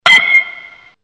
알림음 8_알루미늄배트.mp3